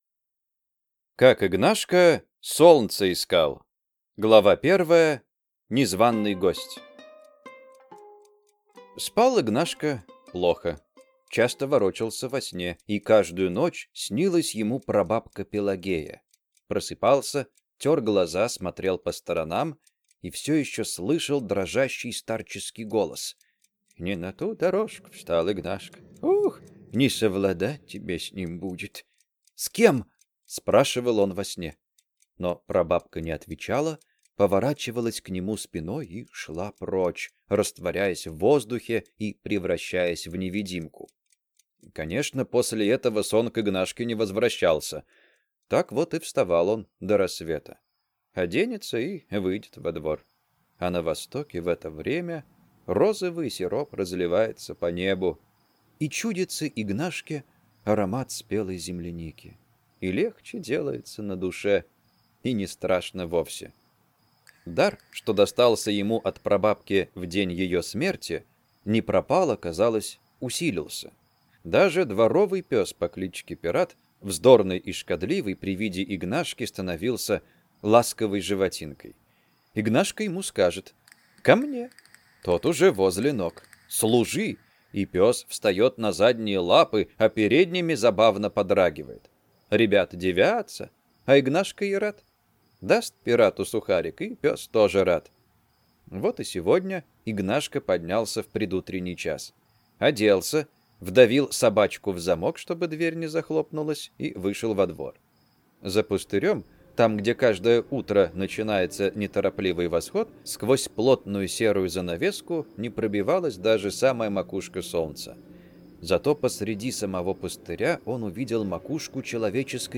Аудиокнига Как Игнашка солнце искал | Библиотека аудиокниг